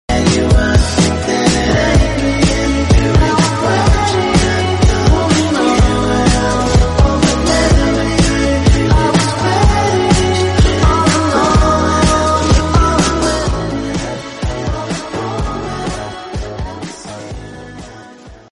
Boo sound effects free download